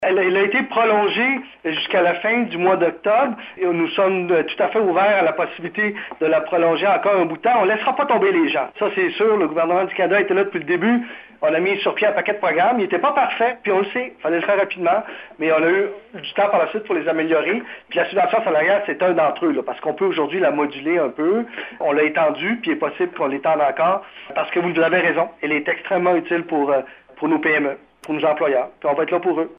C’est ce qu’a indiqué le lieutenant politique de Justin Trudeau au Québec, Pablo Rodriguez, lors d’un point de presse téléphonique hier alors qu’il était en compagnie de la ministre et députée de la Gaspésie et des Îles, Diane Lebouthillier.